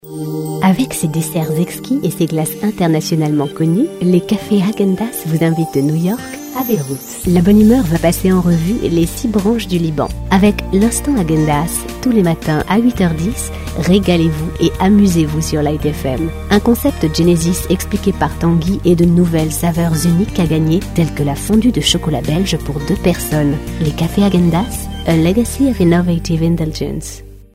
Tous types de voix pour e-learning, institutionnel, promo douce.
Sprechprobe: eLearning (Muttersprache):
My voice is perfect for smooth commercials or institutional recordings.